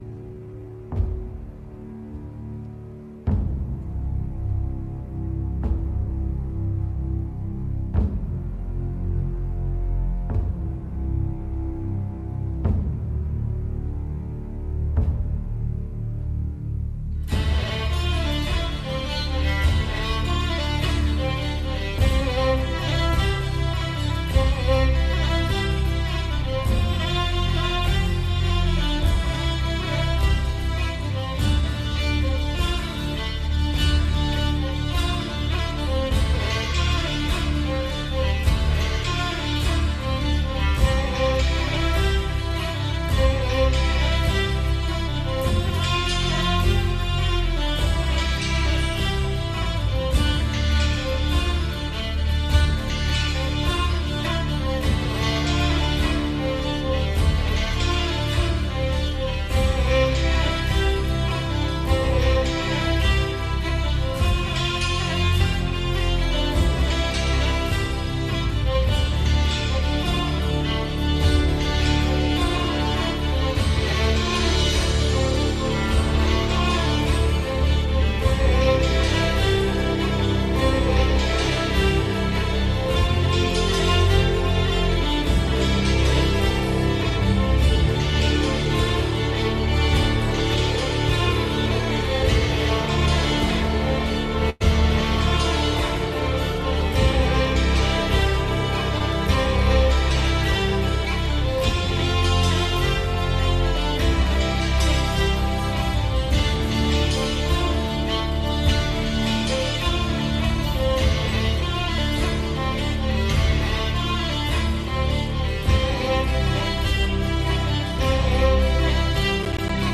The Untold Issue Channel hosts dive into current events, discussing military actions and cyber threats linked to the Iran conflict. They explore how these issues might affect the U.S., including potential cyber attacks on networks and disruptions in banking.